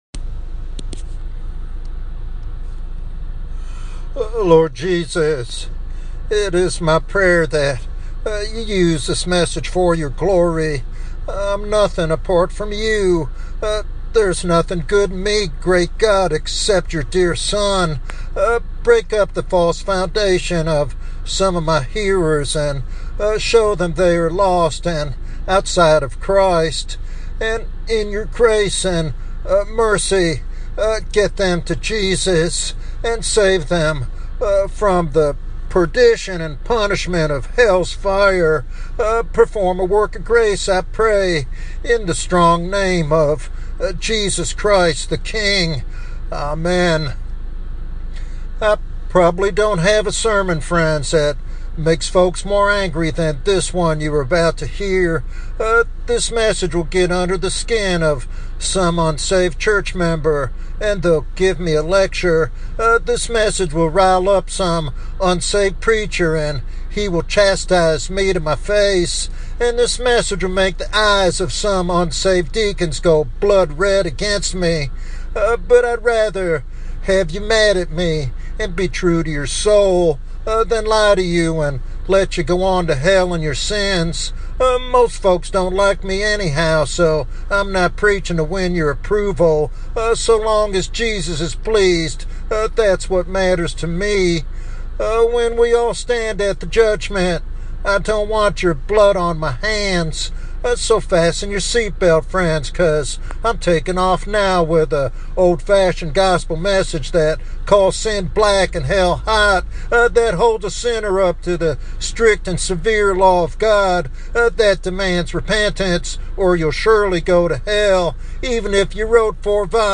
This evangelistic sermon calls all to genuine repentance and faith in Jesus Christ, emphasizing the necessity of being born again to avoid eternal judgment.